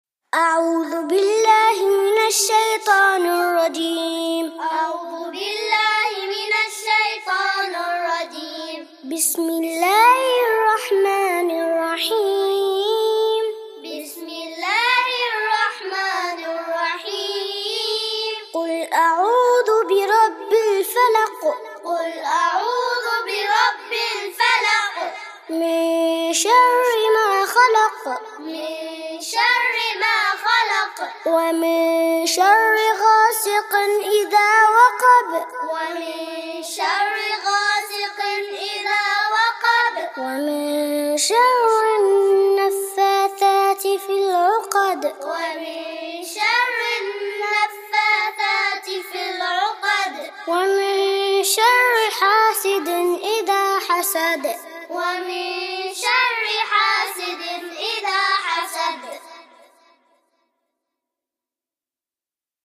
الفلق (تعليمي للصغار) - لحفظ الملف في مجلد خاص اضغط بالزر الأيمن هنا ثم اختر (حفظ الهدف باسم - Save Target As) واختر المكان المناسب